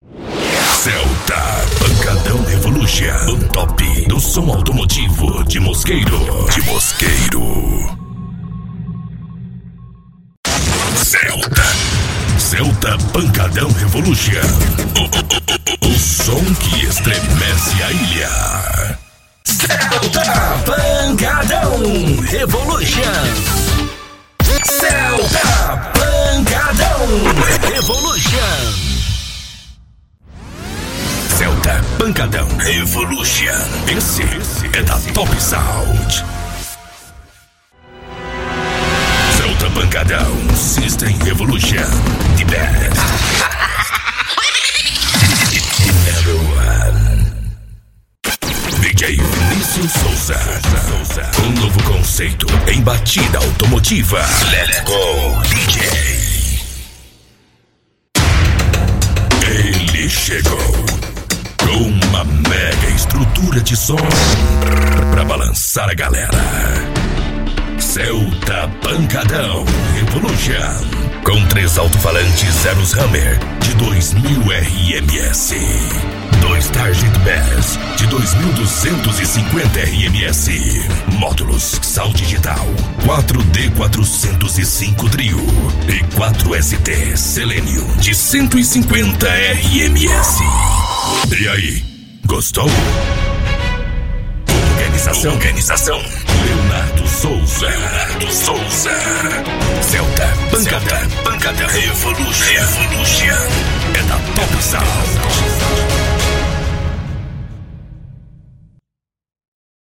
Voz Impacto e Grave.